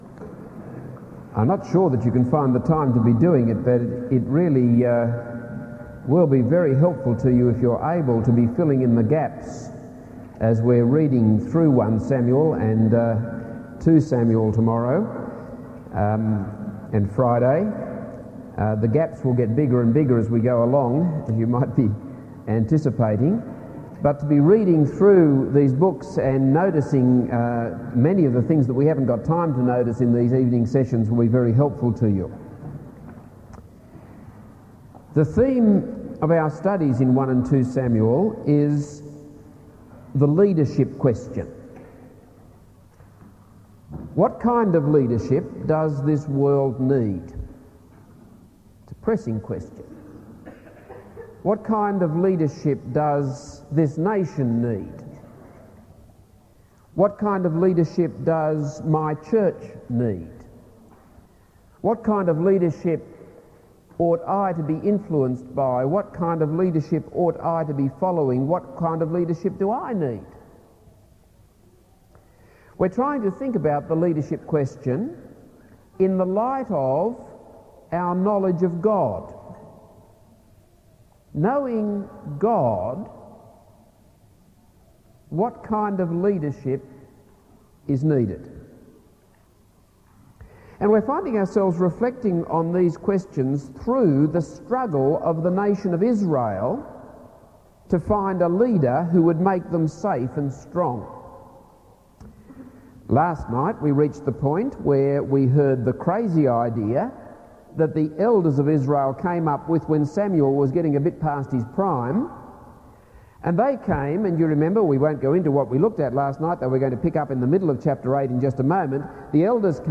This is a sermon on 1 Samuel 8-16.